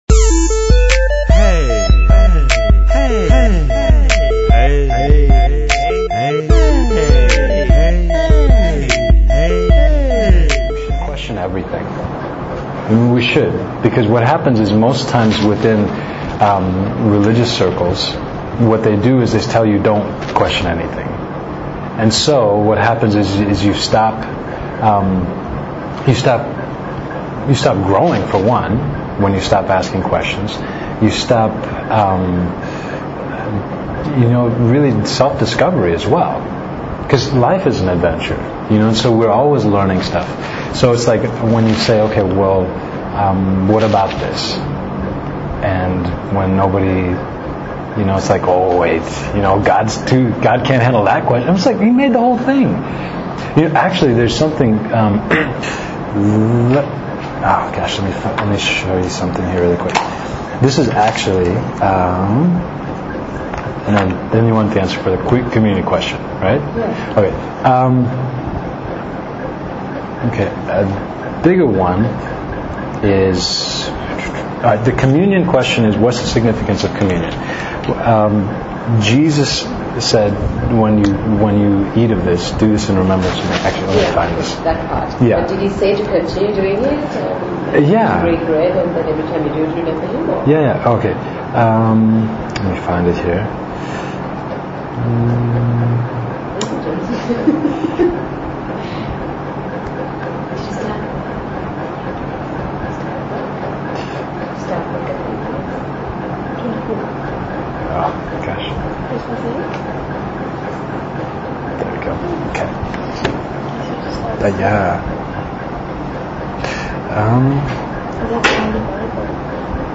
Q&A - Communion; Conformity vs. Individuality; False Leadership; The Illusion of Time; the Nature of the Body of Christ; Dying to self; Keeping the Connection; Godly Decision Making